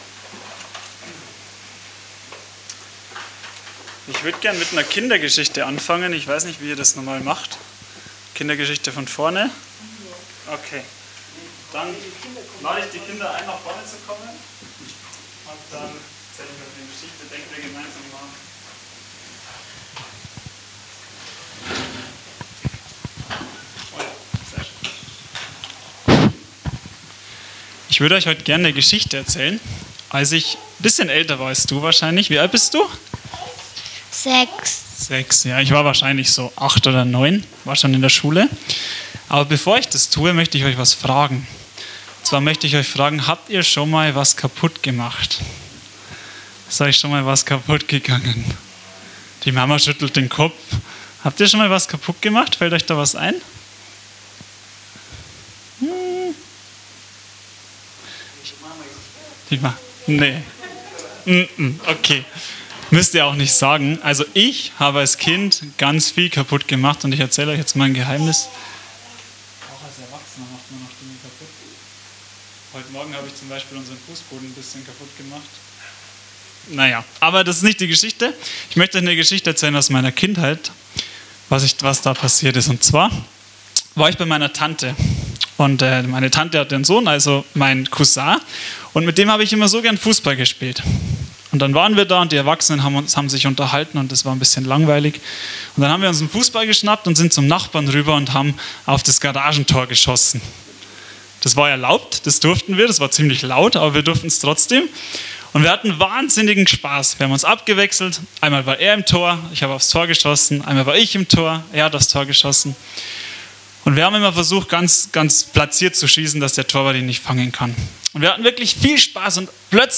Leittext zur Predigt: Offenbarung 14, Verse 6-10